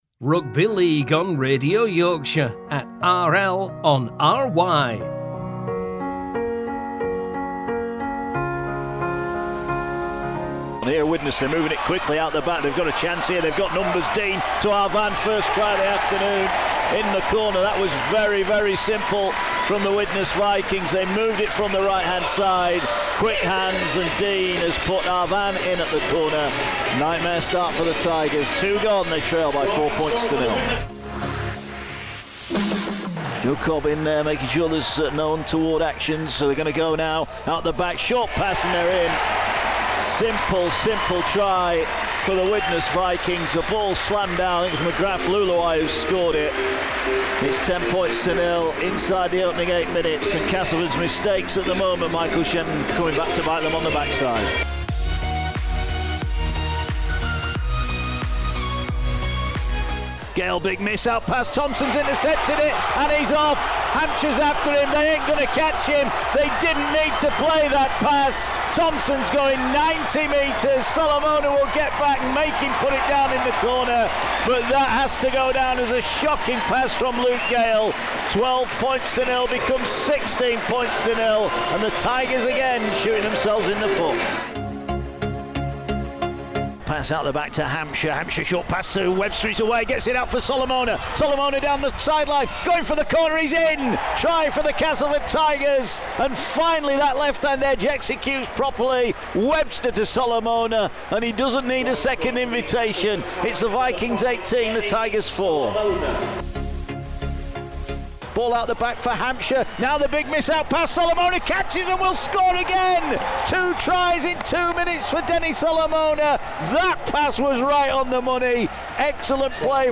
were on commentary duty as Castleford came back from 18-0 to secure an away win against Widnes.